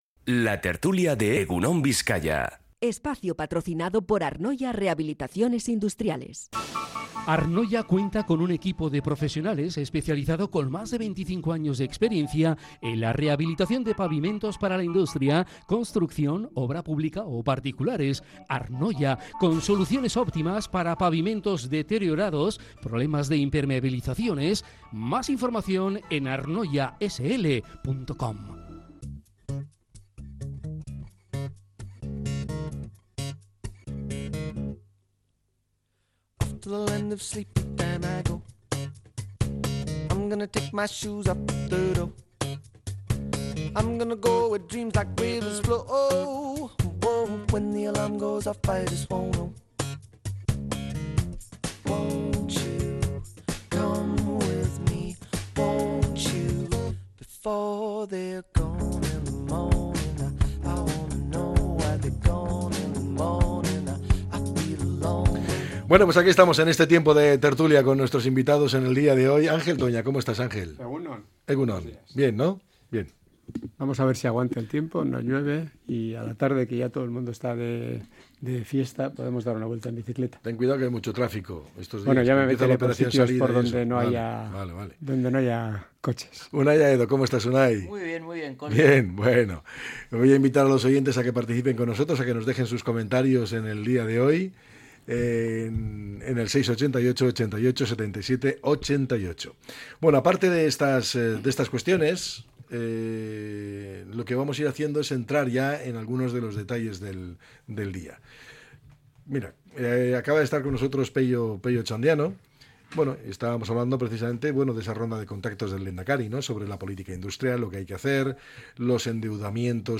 La tertulia 16-04-25.